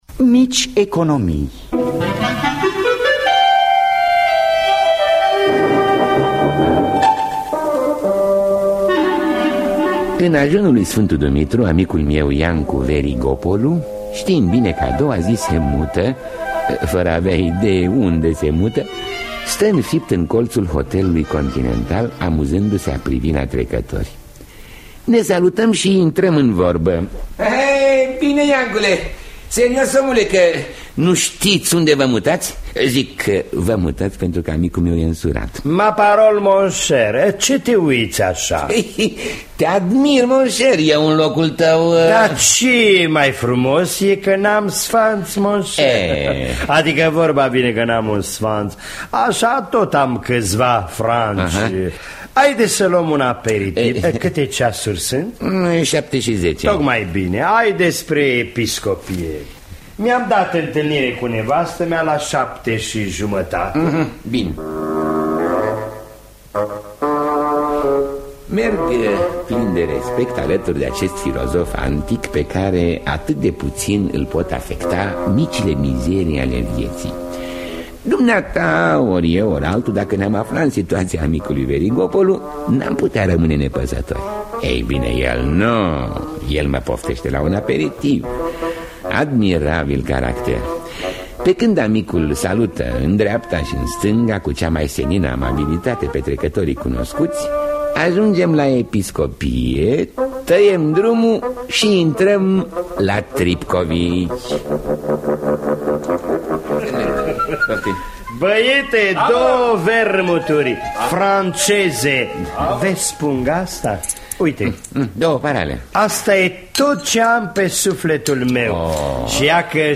Mici economii de I.L.Caragiale – Teatru Radiofonic Online
În distribuţie: Ion Lucian, Dem Radulescu, Vasilica Tastaman.